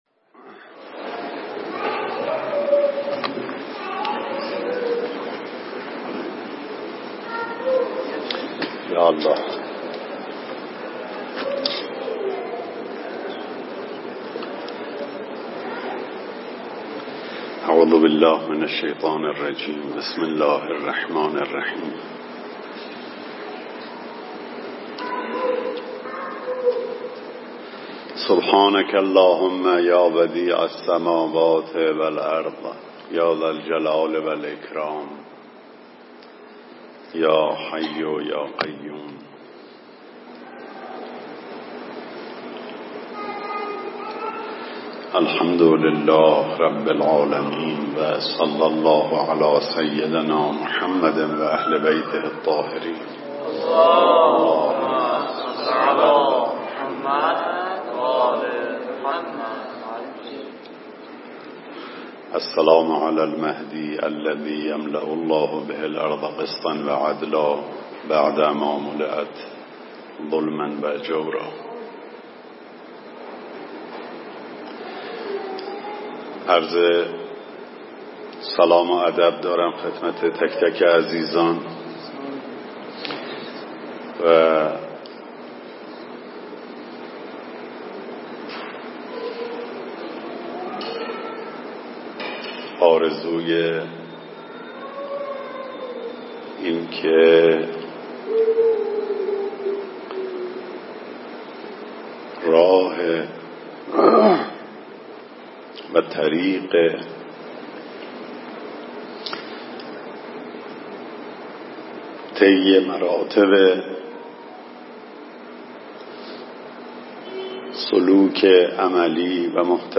درس الاخلاق
🔶مجلس توسل به امام رئوف حضرت رضا علیه السلام